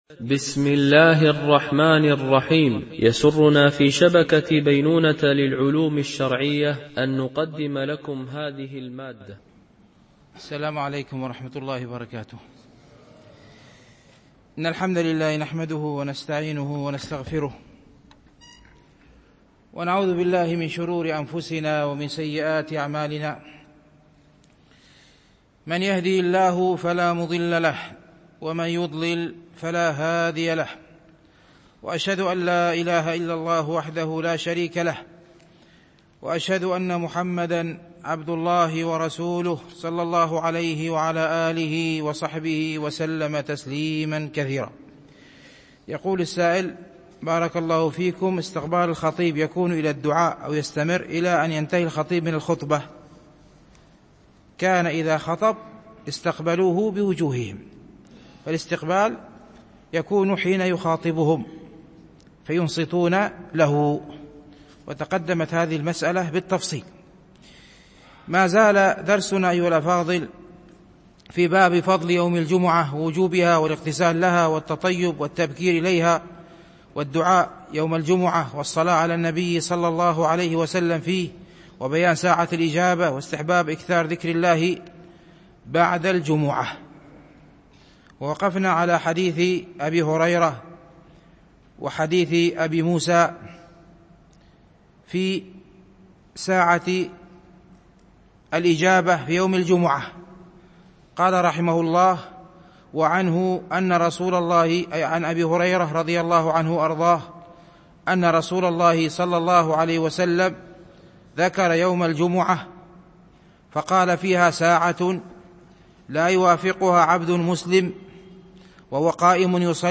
شرح رياض الصالحين – الدرس 300 ( الحديث 1164-1166)